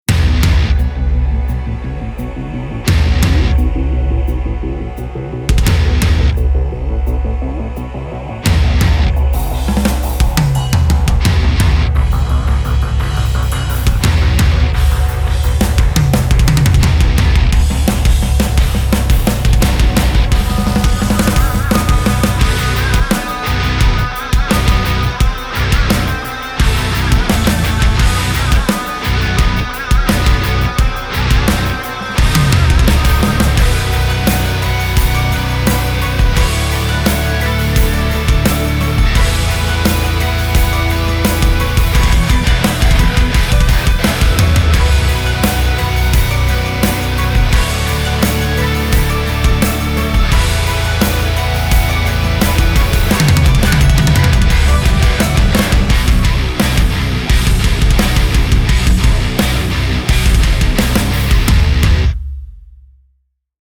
• ダブル・キック・ドラムを真のステレオ・ポジショニングで収録
獰猛で、巨大なドラムをより強く叩く
ドラムがギターの壁を切り裂く。